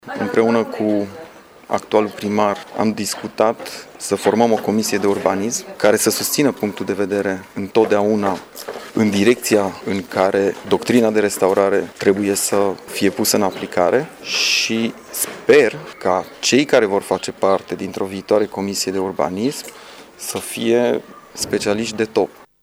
Semnalul de alarmă a fost tras în această dimineaţă de arhitectul-şef al judeţului Mureş, Răzvan Şipoş, care a susţinut o conferinţă de presă la Sighişoara.